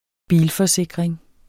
Udtale [ ˈbiːl- ]